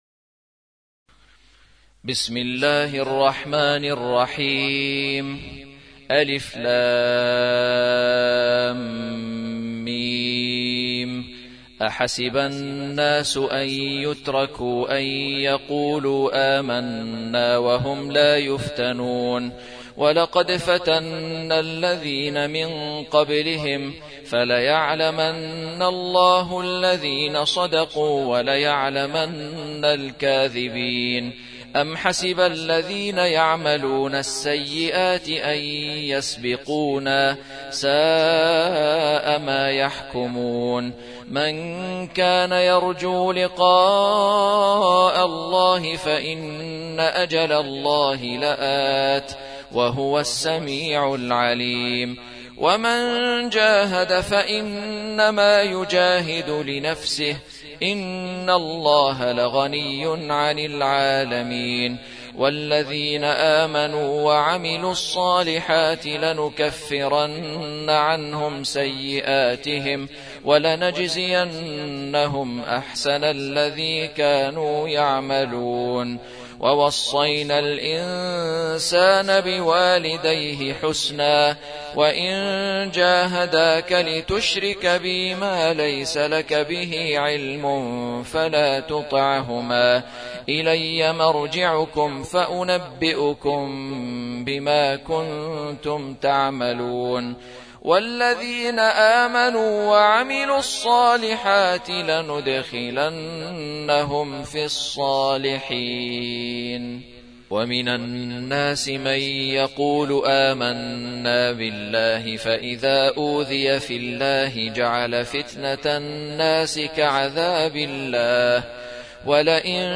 29. سورة العنكبوت / القارئ